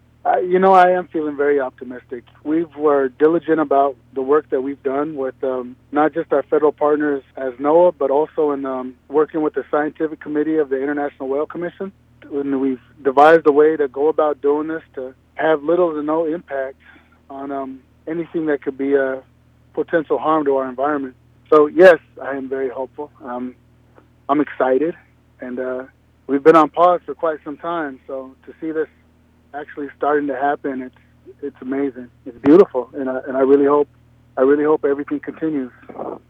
We asked Makah Tribal Councilman Patrick DePoe if he was feeling optimistic about today’s announcement.